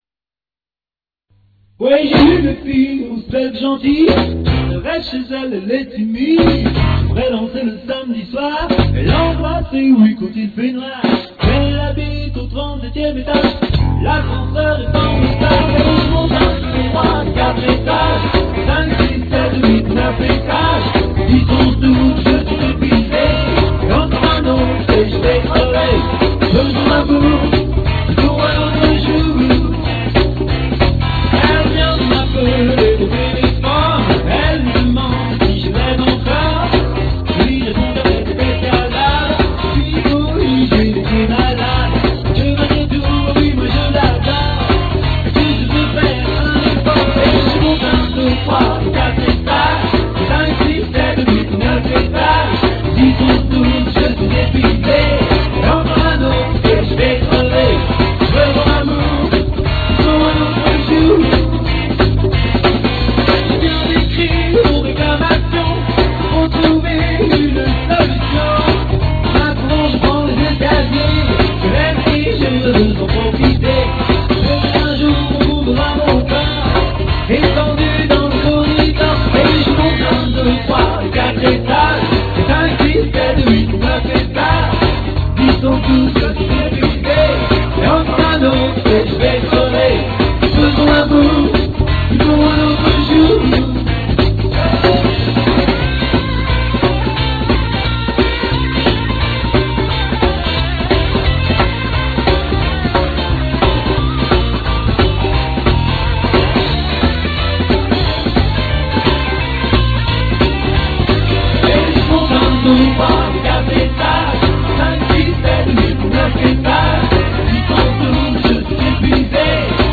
Guitare, Chant : La couleur.
Basse électrique : Le parfum.
Batterie : La matière.
Les "Guest Star" Percus